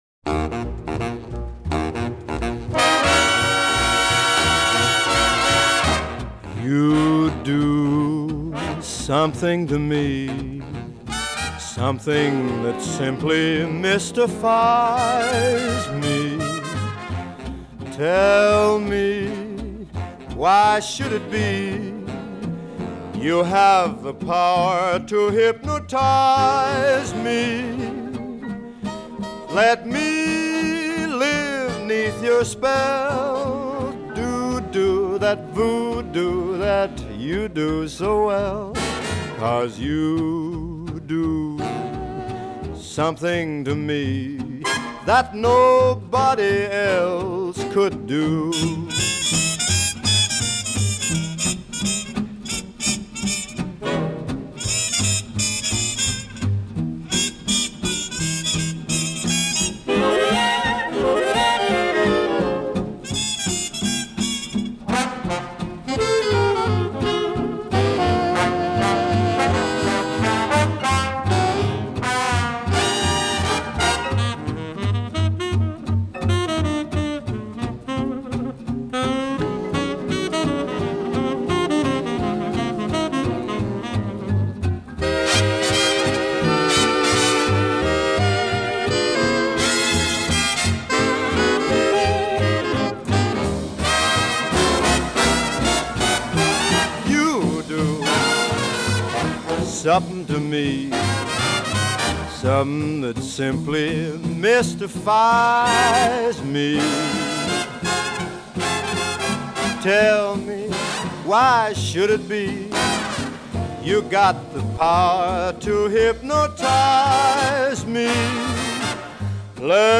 1929   Genre: Musical   Artist